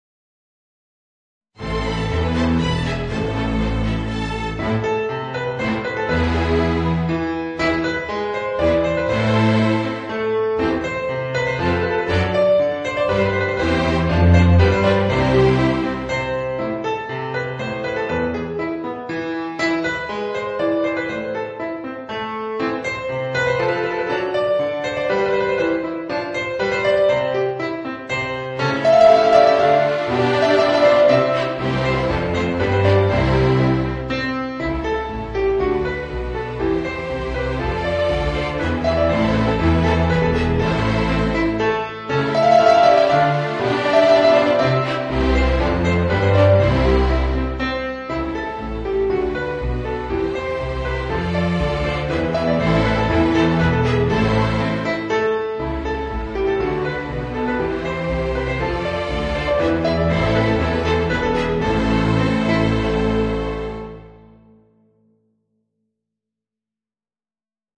Voicing: Piano and String Orchestra